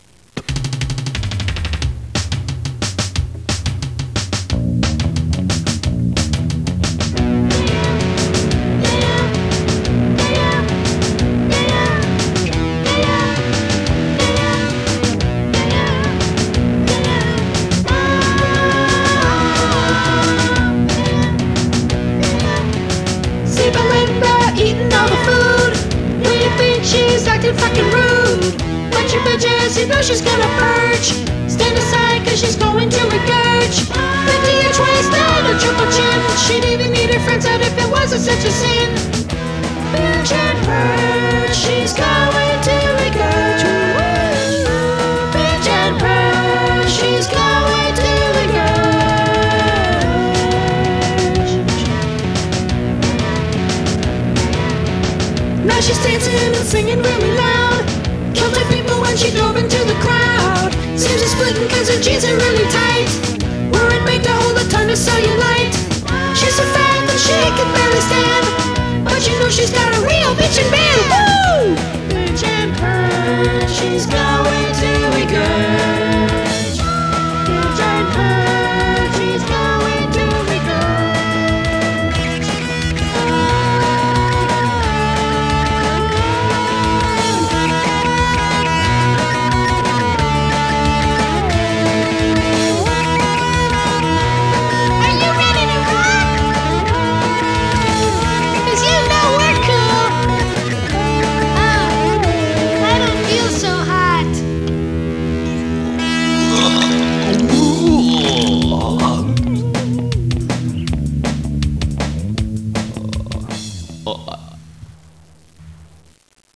guitars, bass, vocals.
drum programming, vocals.